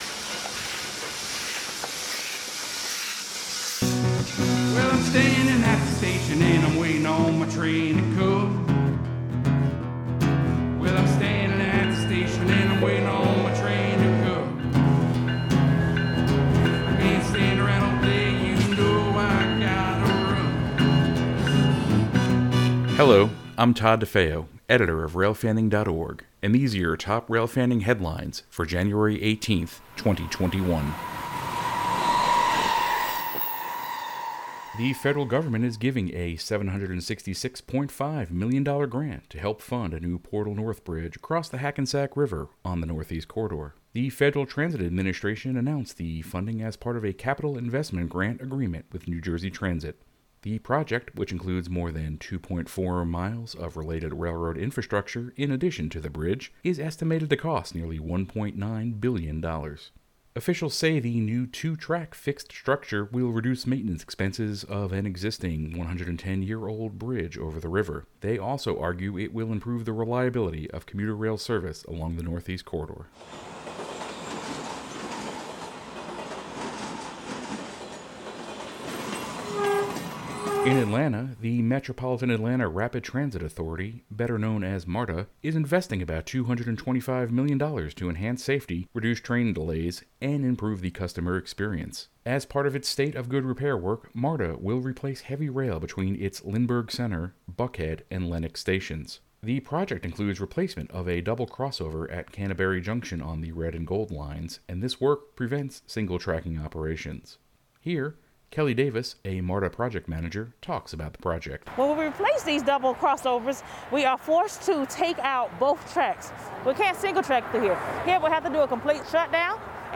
Sound Effects
• Diesel Horn: Recorded at the Southeastern Railway Museum on Nov. 14, 2020.
• Steam Train: 1880s Train, recorded Sept. 12, 2020, in Hill City, South Dakota.
• Arrow III: Recorded April 16, 2018, in New Brunswick, New Jersey.